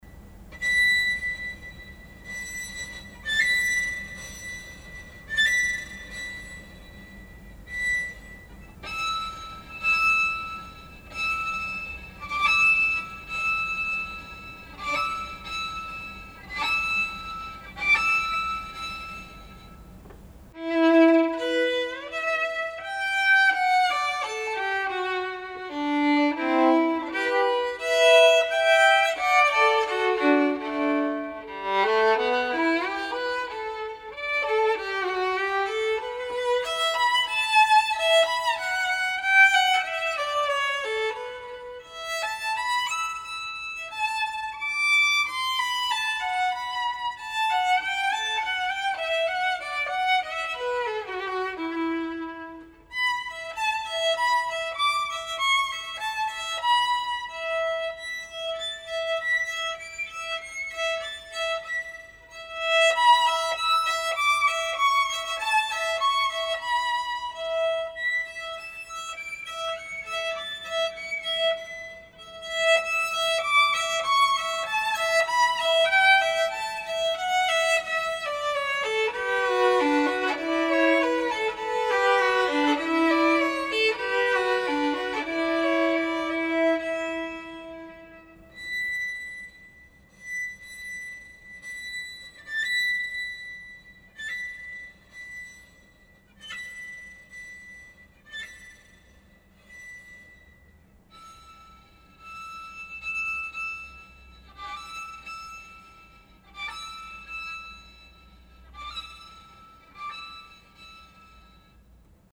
涼を求めて、今日は音で涼しくなれないかなぁとこんな曲を作ってみました。
曲の冒頭と最後に風鈴の音色を真似てみました。
曲中にも出てくる「フラジオレット」という倍音を使った技法です。